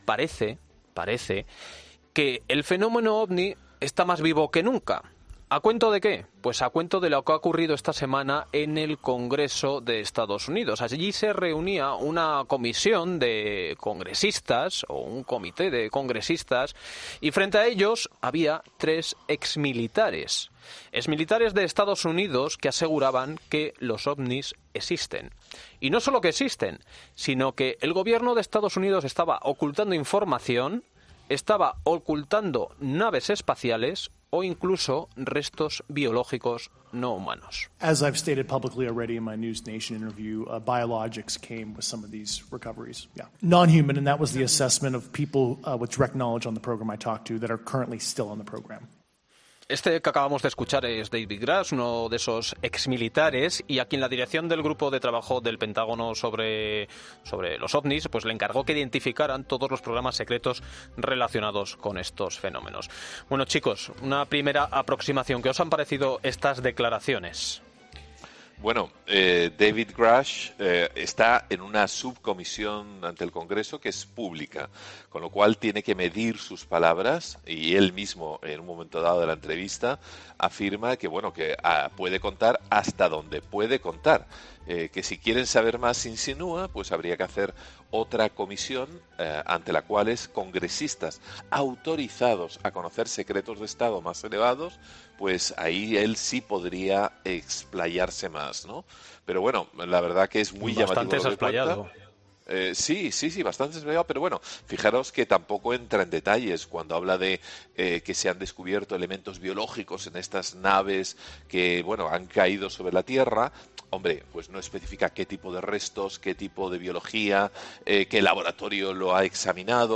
Un tema que han analizado este sábado en la tertulia de Fin de Semana el psiquiatra forense